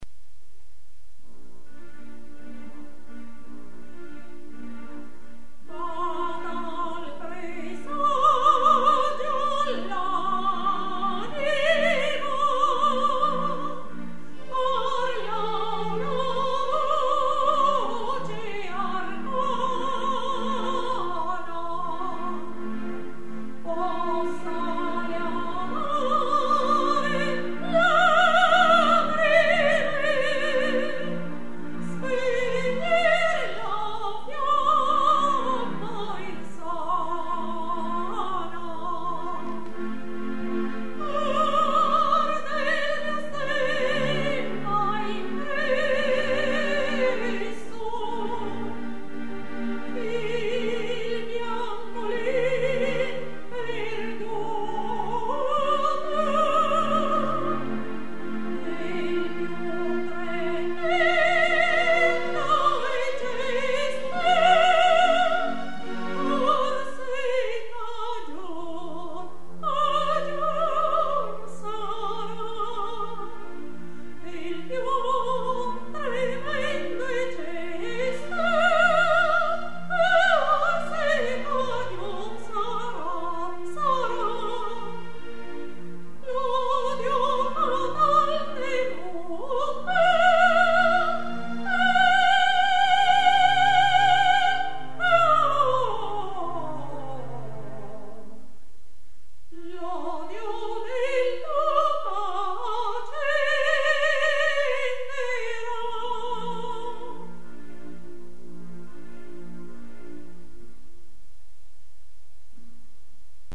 soprano 01:52